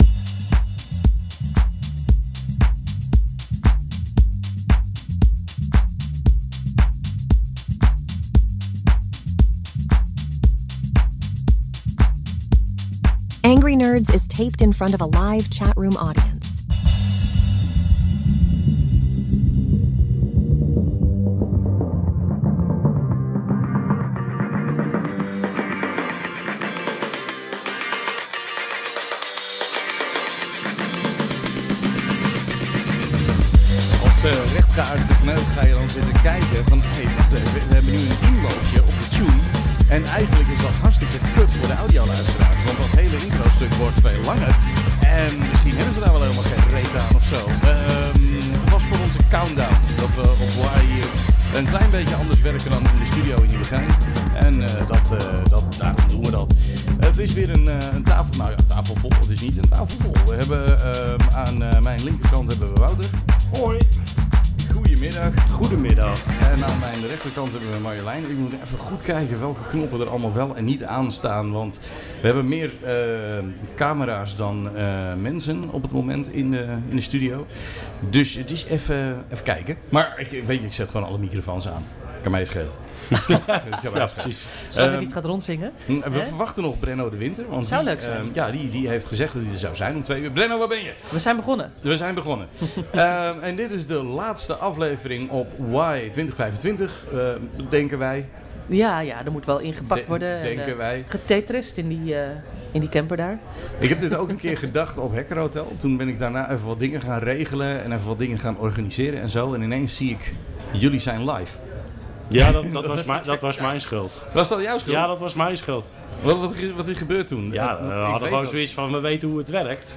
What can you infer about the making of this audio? Angry Nerds Live op WHY2025 - Dinsdag.mp3